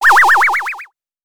ihob/Assets/Extensions/CartoonGamesSoundEffects/Dizzy_v1/Dizzy_v1_wav.wav at master